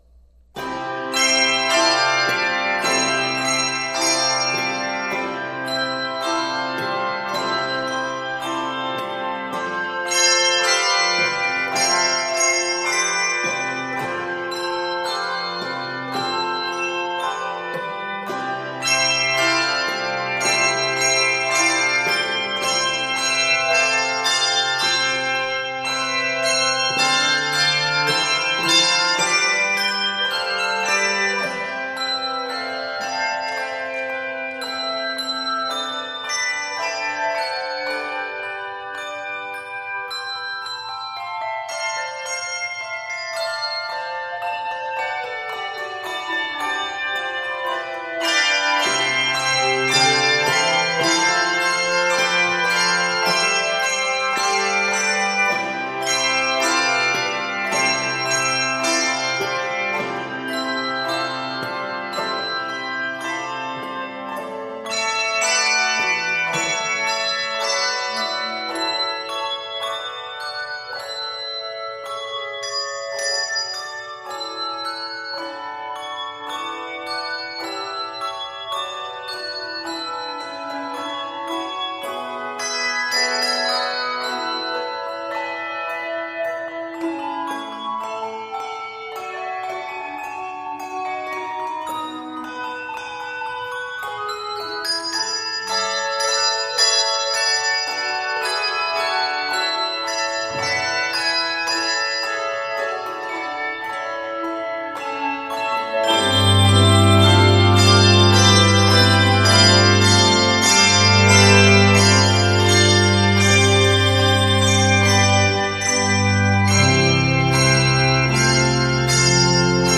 Octaves: 2, 4 or 5